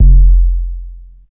CC - Robotic 808.wav